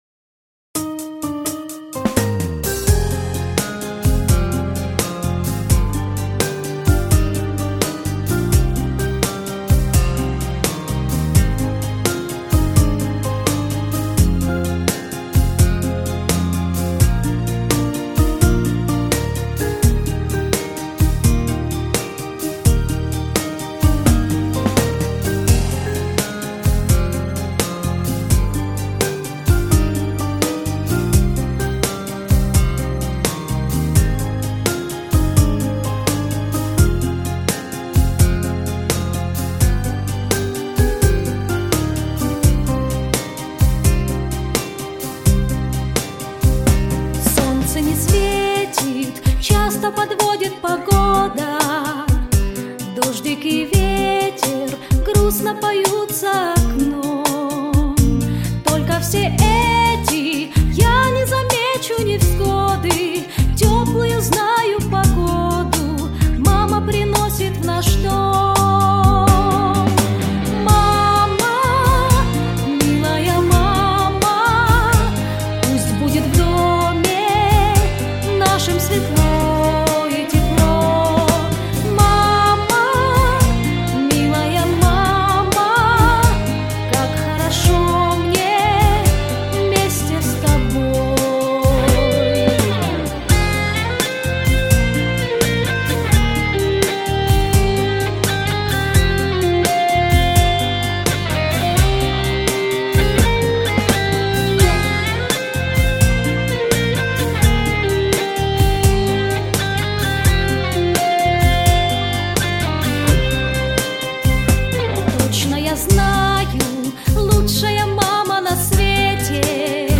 🎶 Детские песни / О близких людях / Песни про маму